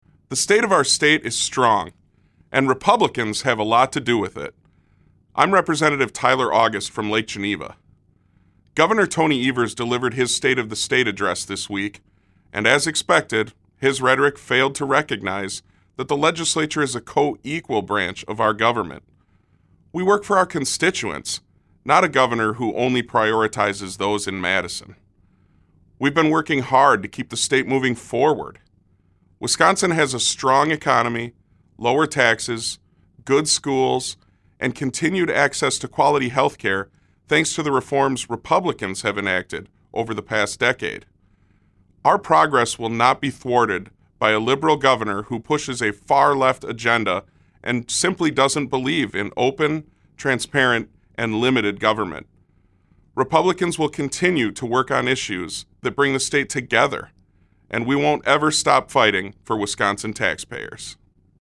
August: GOP Weekly Radio Address: The State of the State is strong, thanks to Republican leadership